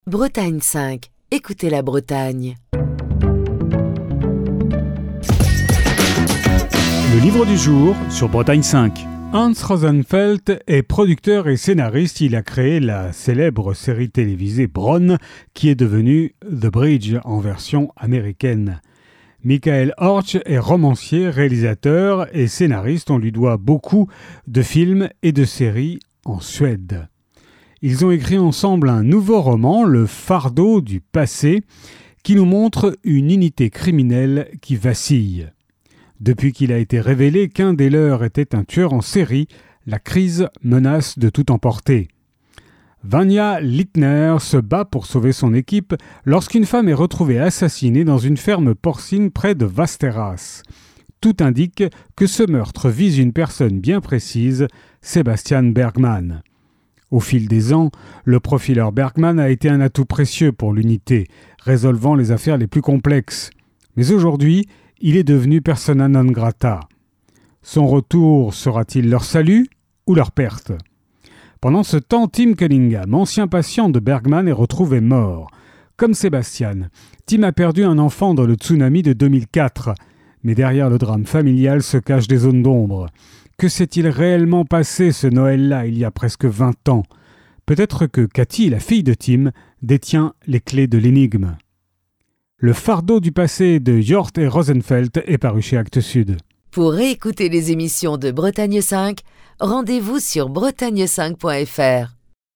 Chronique du 30 octobre 2025.